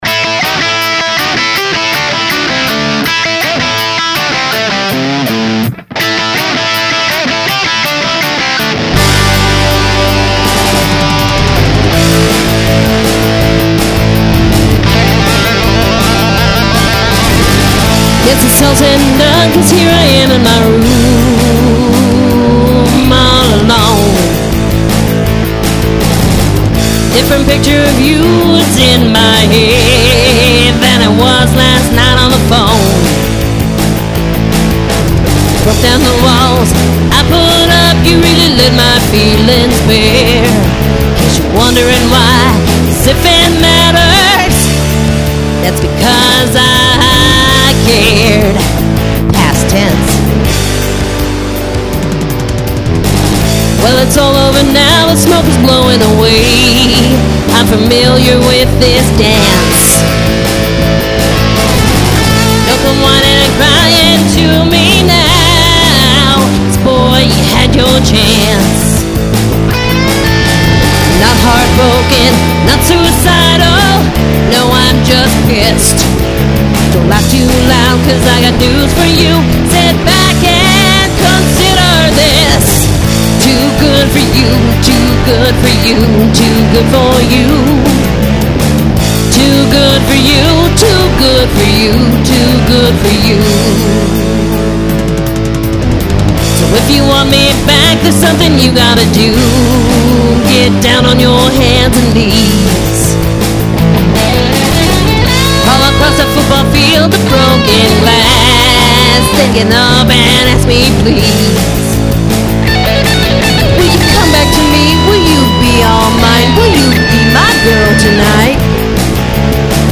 Conservative rock, Boise